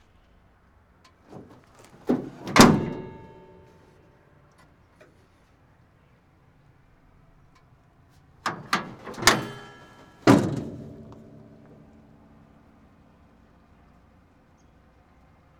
transport
Bus Hood Open Close 2